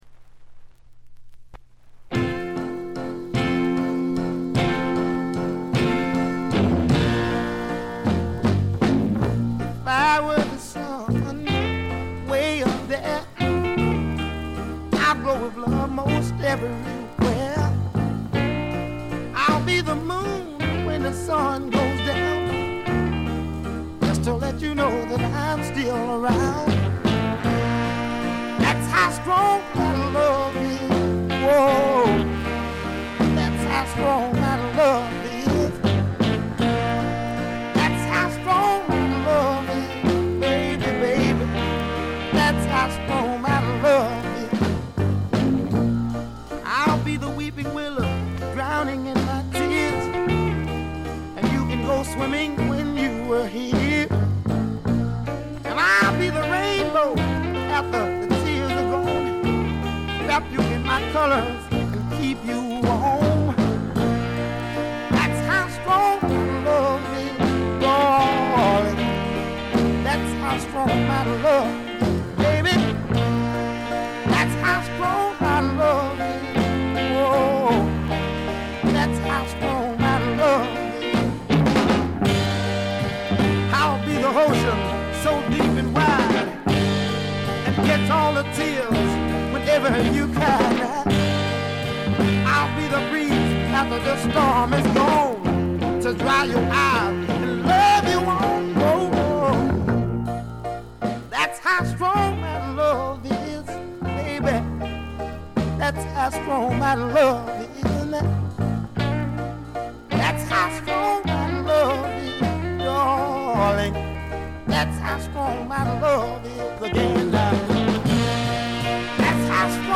静音部でのバックグラウンドノイズ程度。鑑賞を妨げるようなノイズはありません。
試聴曲は現品からの取り込み音源です。